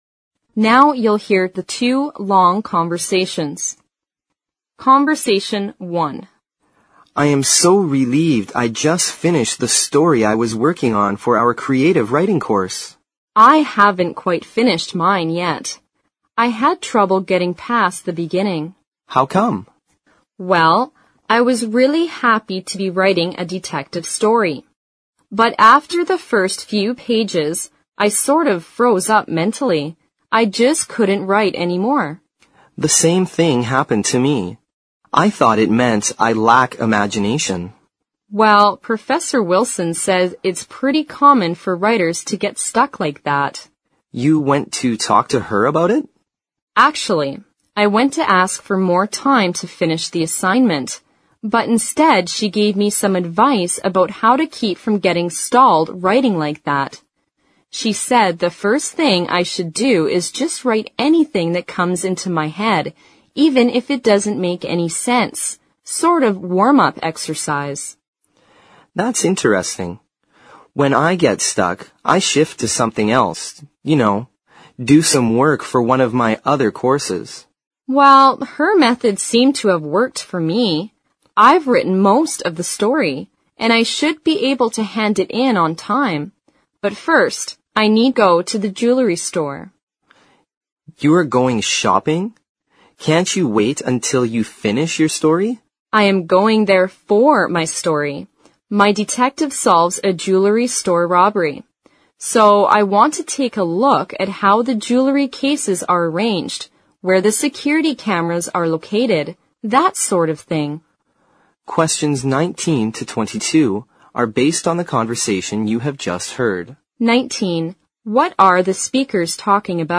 Conversation One